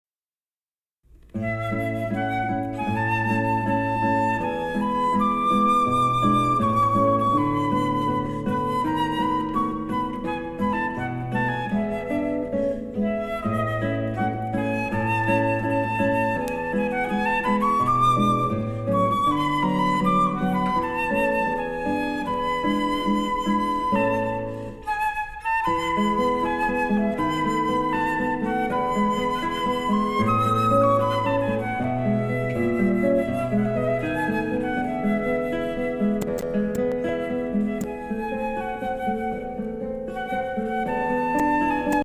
The calming sounds of our flute and guitar duo will create the perfect setting for your big day.